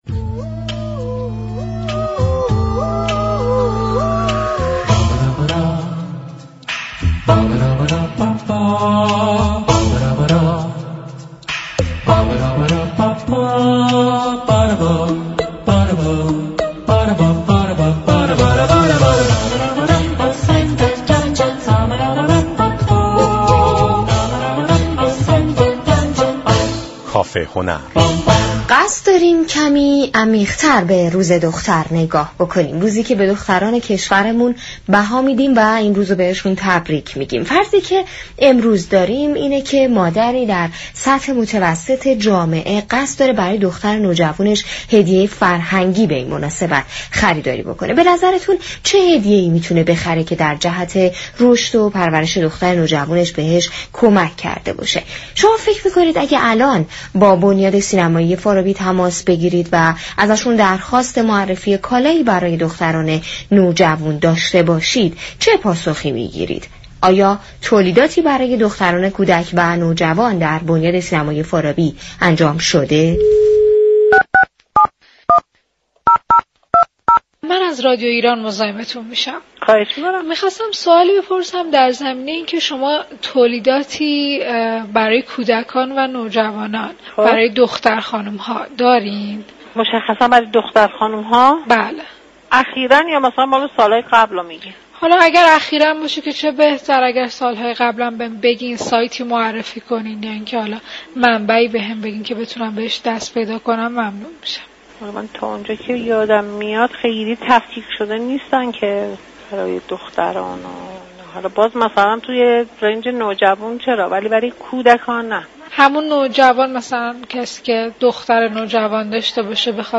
برنامه كافه هنر در قالب یك گفت و گوی گزارشی به وضعیت تولید آثار هنری برای دختران نوجوان پرداخت و در این باره توضیحاتی را ارائه داد.